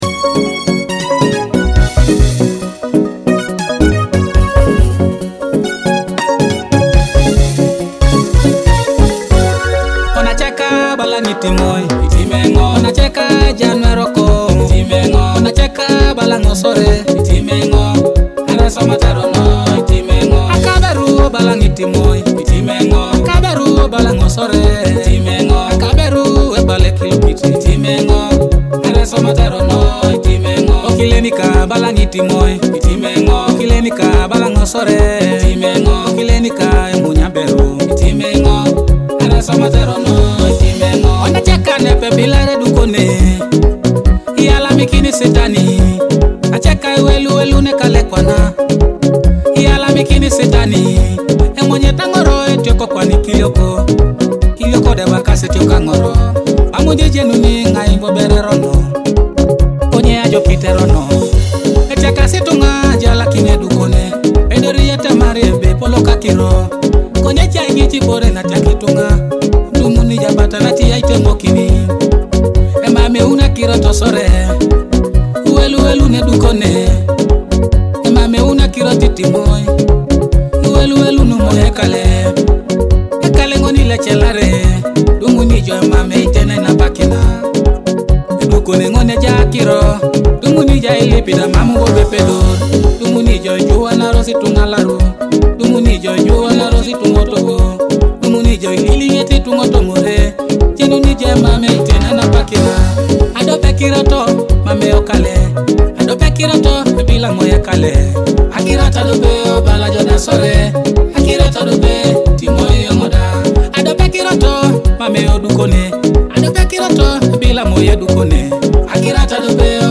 Ugandan song
In this emotional track
With touching lyrics and soulful melodies